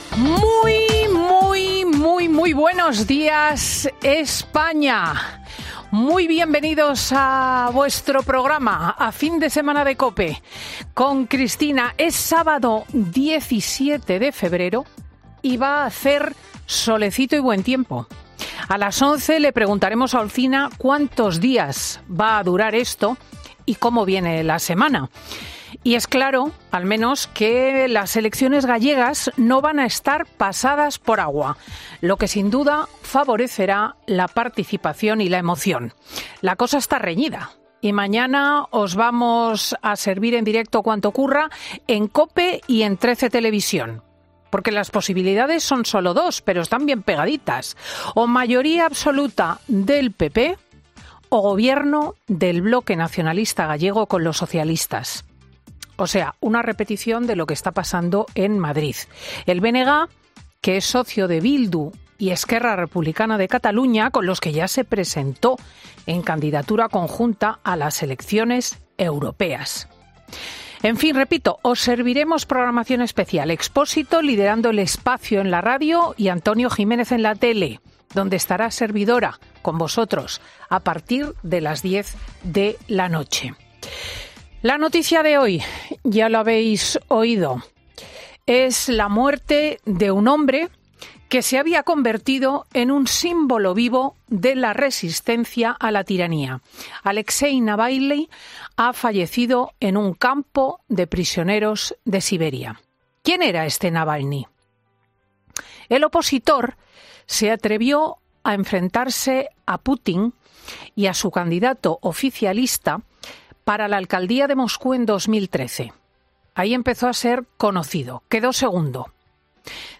Escucha el monólogo de Cristina López Schlichting de este sábado 17 de febrero de 2024